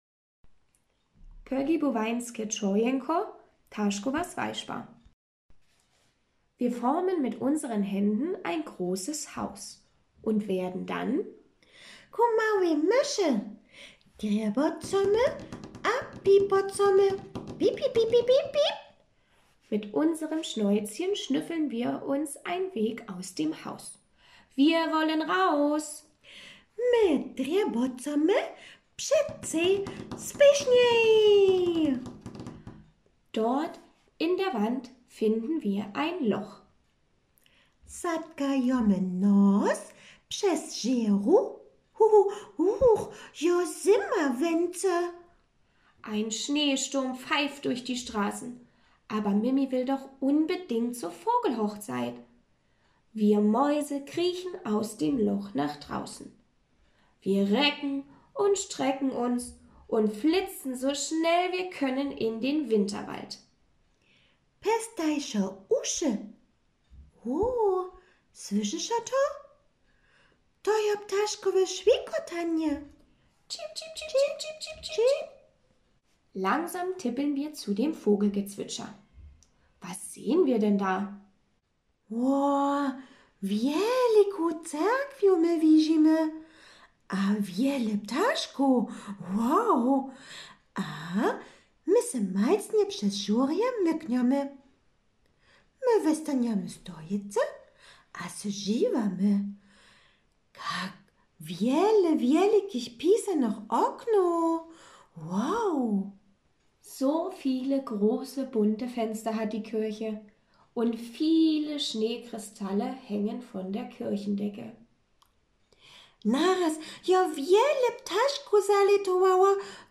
Pógibowańske tšojeńko:
01 BewegGeschichte_VH_Mimi feiert VH_1.mp3